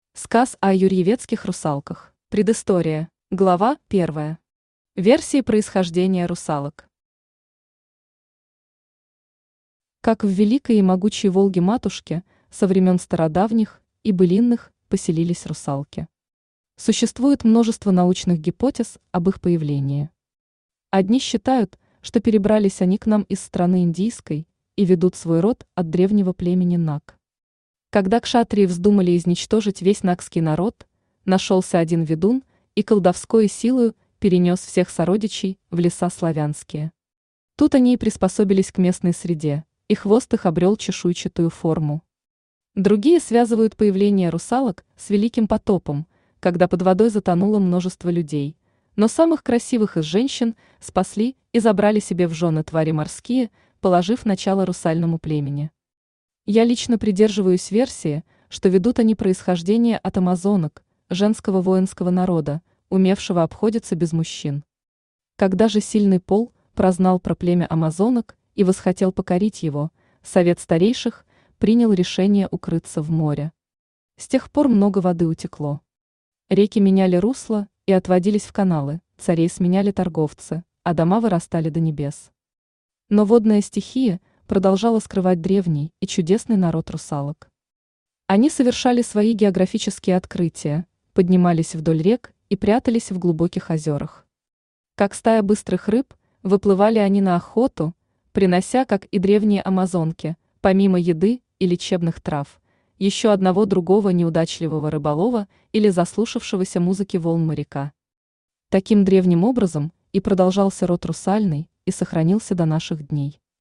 Аудиокнига Сказ о юрьевецких русалках | Библиотека аудиокниг
Aудиокнига Сказ о юрьевецких русалках Автор Татьяна Андреевна Смирнова Читает аудиокнигу Авточтец ЛитРес.